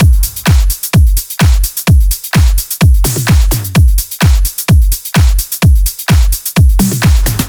VFH2 128BPM Unimatrix Kit 1.wav